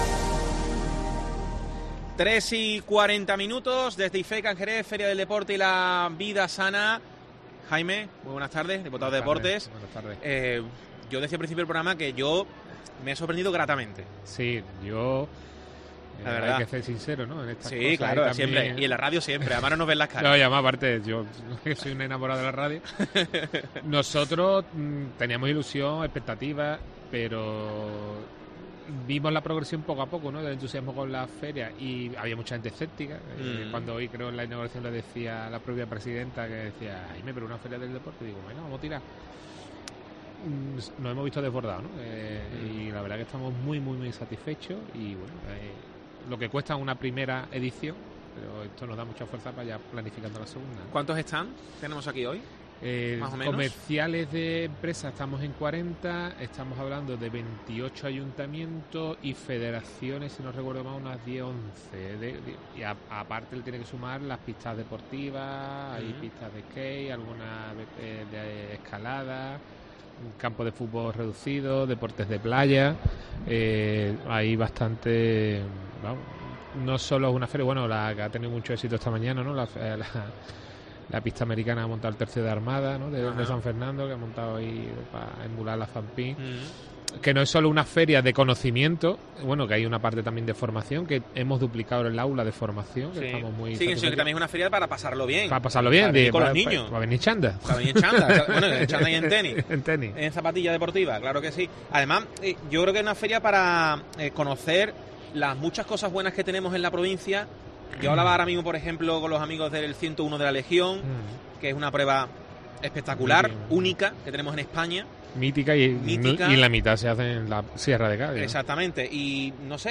Jaime Armario, diputado de Deportes de la Diputación de Cádiz, desde la Feria del Deporte y la Vida Sana